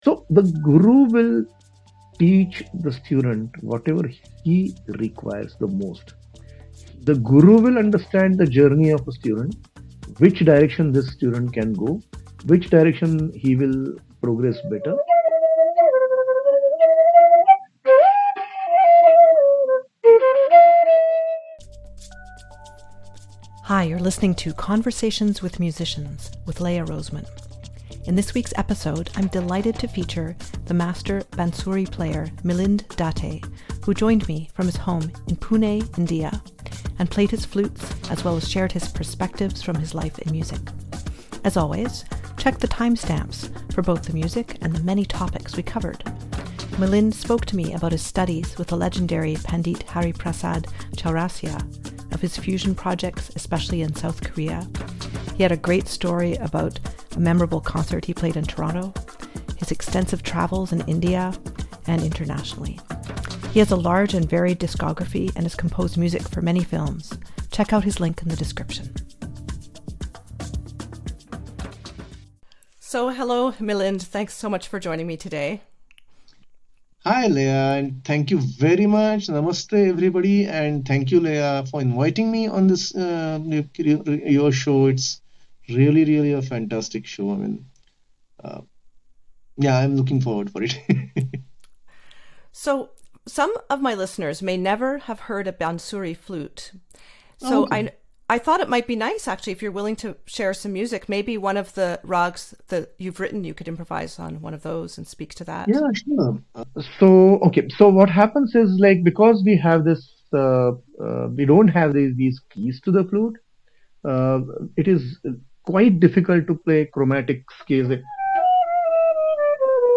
(01:46) bansuri flute
(26:11) folk tune from Uttarakhand region
(01:29:56) flute performance